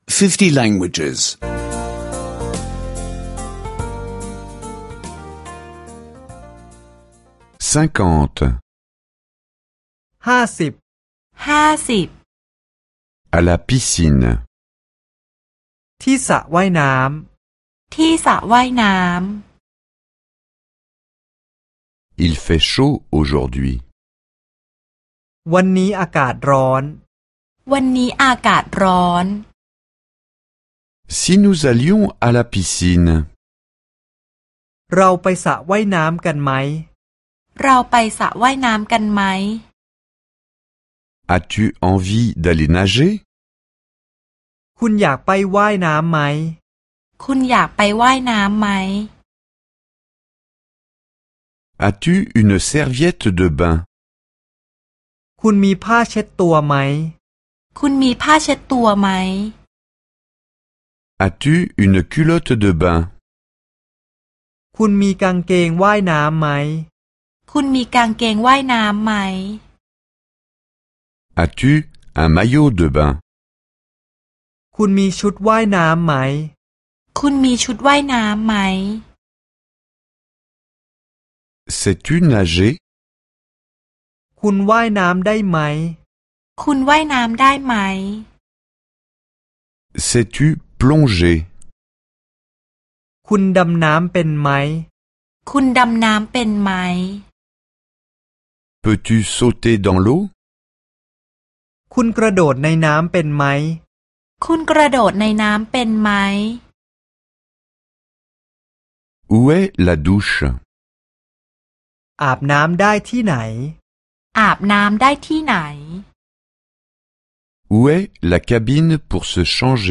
Cours audio de thaïlandaise (écouter en ligne)